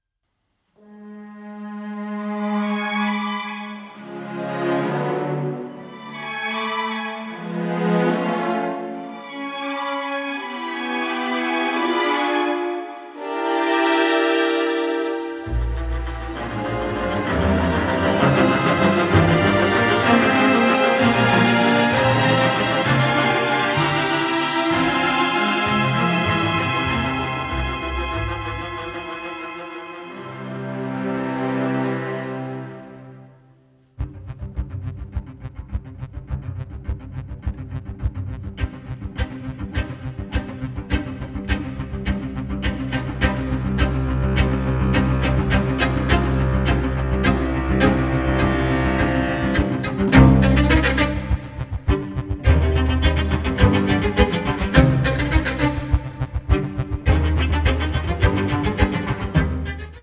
intense, emotional and moving large-orchestral scores
The music was recorded at Air Studios, London